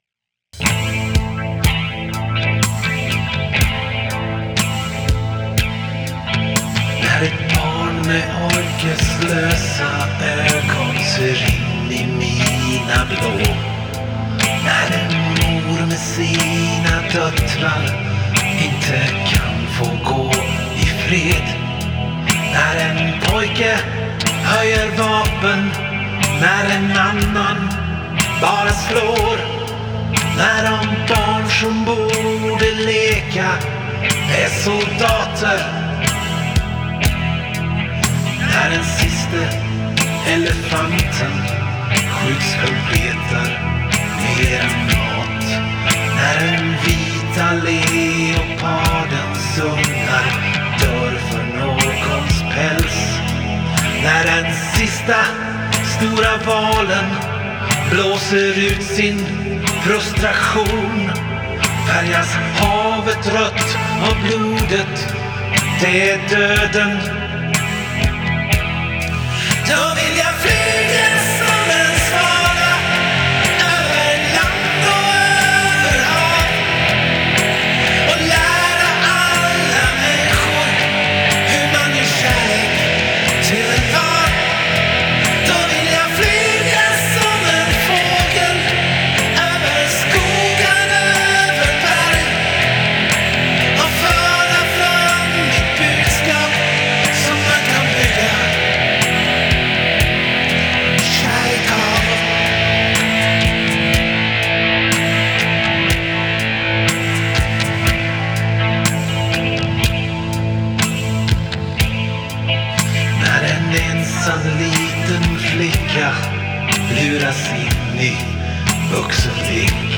Här i min orginalinspelning